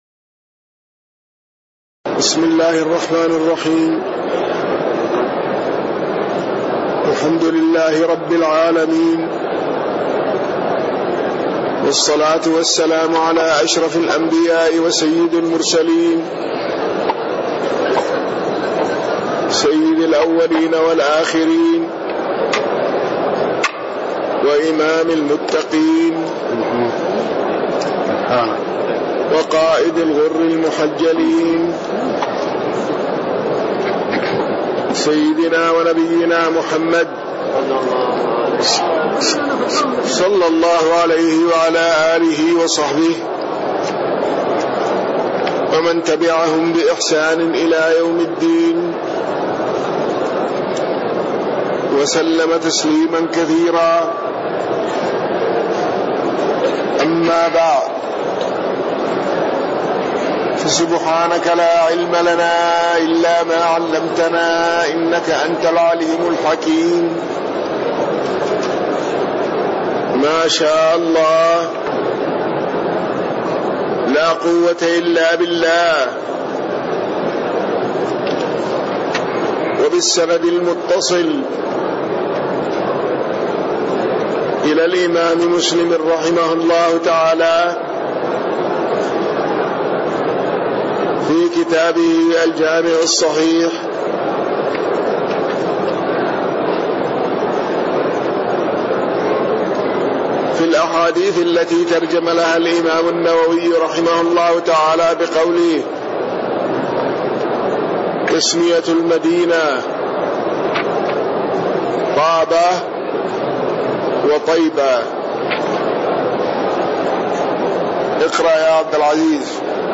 تاريخ النشر ١٣ جمادى الأولى ١٤٣٤ هـ المكان: المسجد النبوي الشيخ